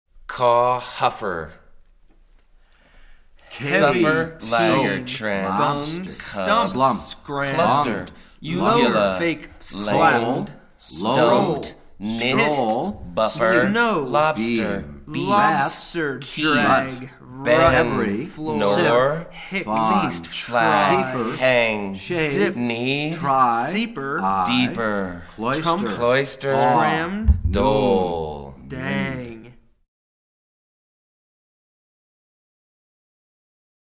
hear the Consort read the visual poem (663 kb)